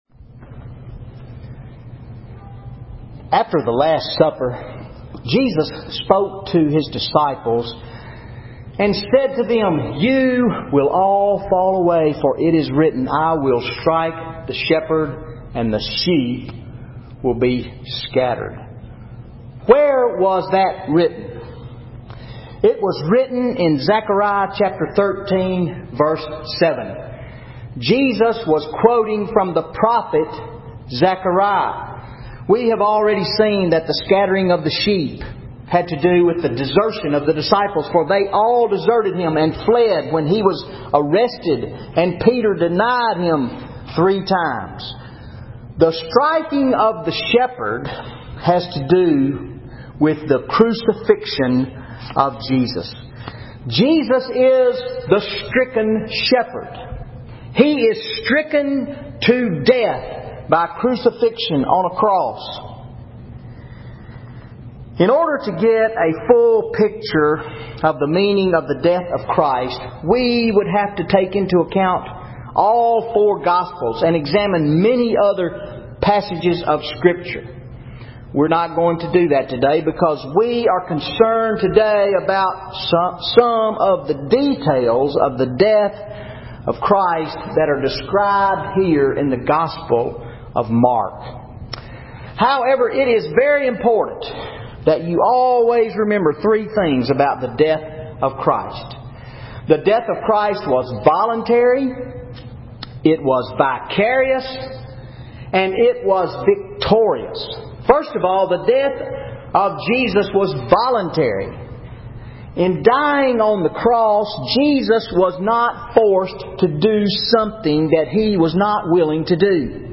Sunday Sermon Mark 15:21-41 The Stricken Shepherd